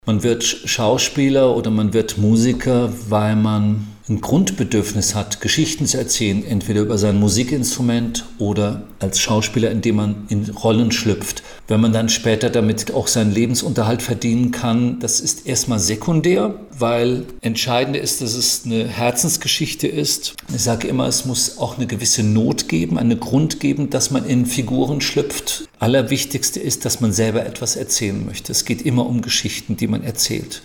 25 Jahre Harry Potter - Rufus Beck im Interview - PRIMATON